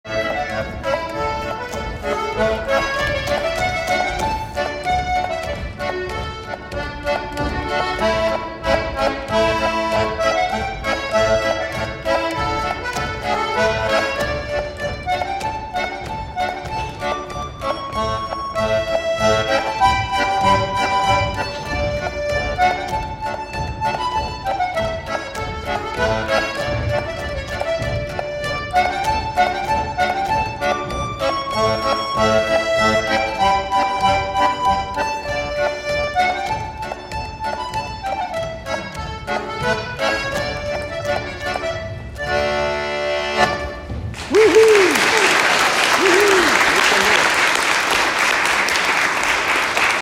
Konserten flöt på väldigt bra och ungdomarna spelade jättebra de låtar de skulle spela ihop och solo.
En kanon bra låt som var både medryckande och svängig. Det som var kul i låten vara att han hängde och drog på takterna lite här och där.
Polkett
Durspel.